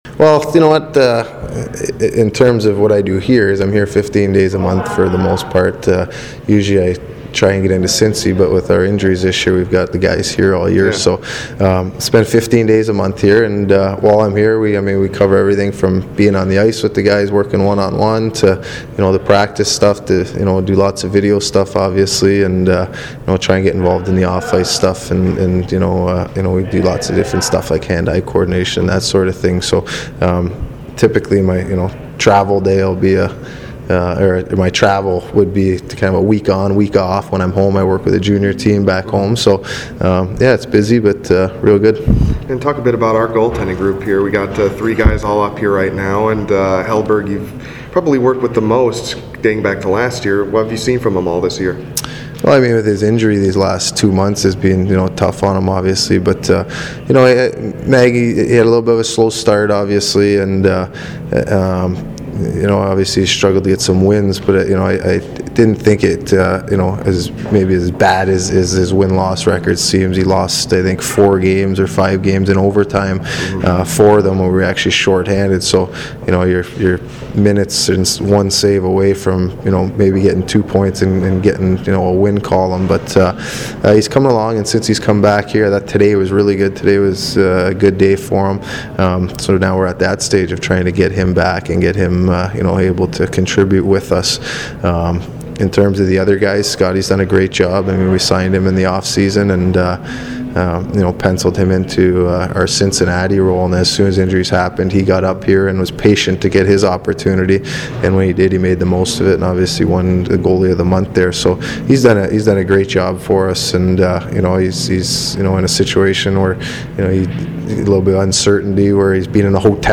Audio, Full Interview